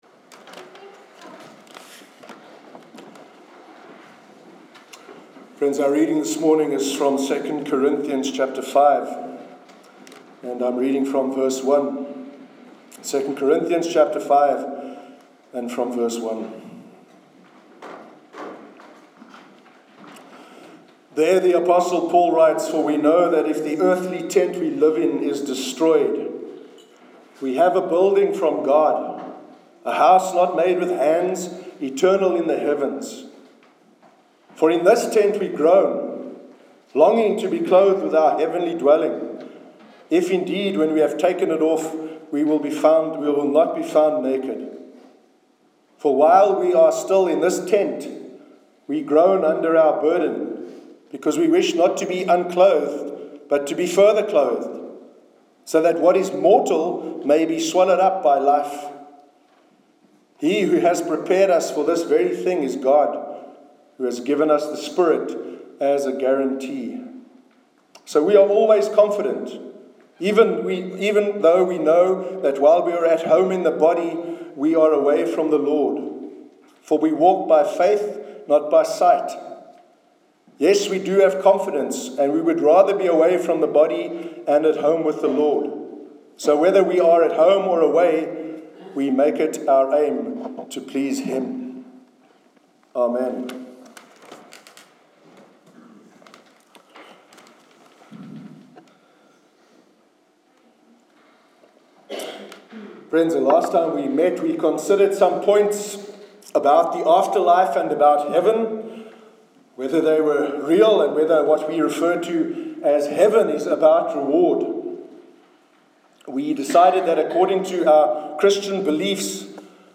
Sermon on Bodily Resurrection
sermon_sunday_9th_oct_2016.mp3